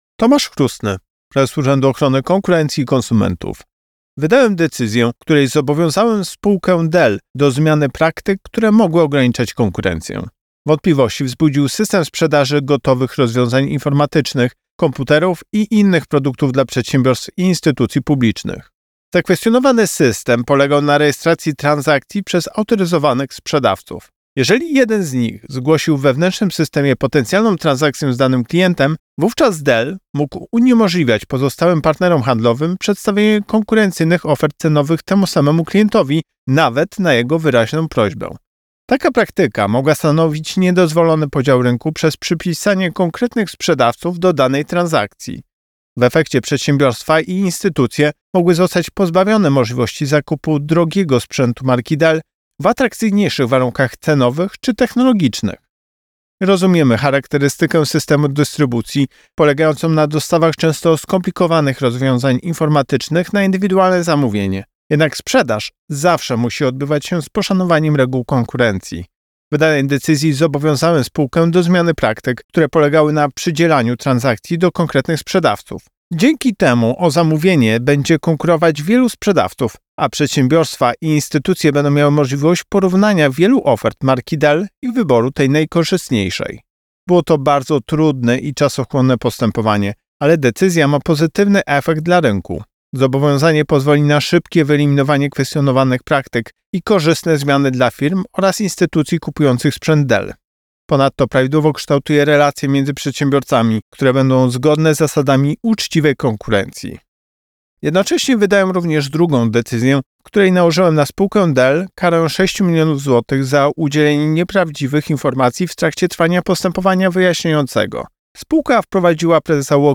Prezes UOKiK Tomasz Chróstny zobowiązał spółkę Dell do zmiany praktyk. Jednocześnie wydał drugą decyzję, w której nałożył na przedsiębiorcę karę 6 mln zł za wprowadzenie w błąd podczas prowadzonego postępowania. Wypowiedź Prezesa UOKiK Tomasza Chróstnego